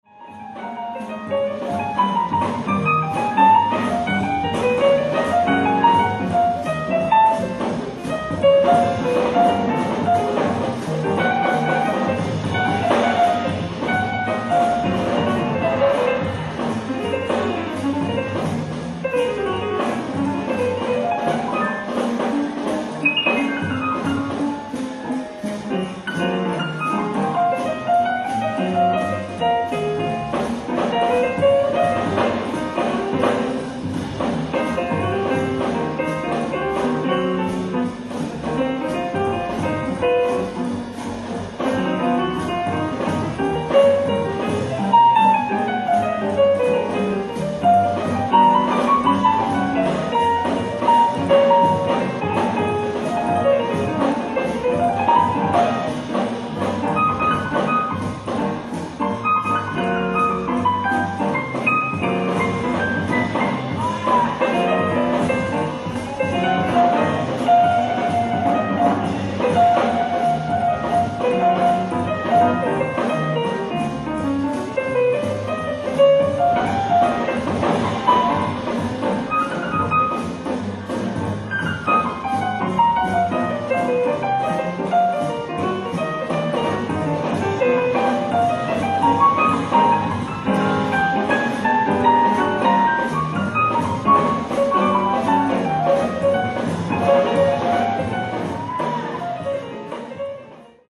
3,500円 Live At Royal Festival Hall, London, UK July 25th
※試聴用に実際より音質を落としています。